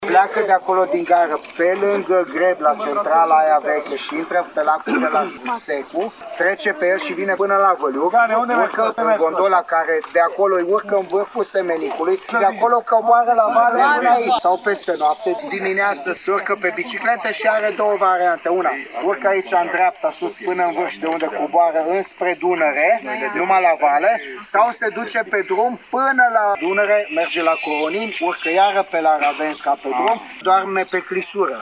Președintele CJ Caraș-Severin, Romeo Dunca, spune ca, în acest fel, turiștii vor putea să facă mișcare, iar morile de apă de la Rudăria vor fi mai bine promovate.
romeo-dunca.mp3